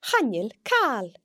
It can also be heard in càil (anything):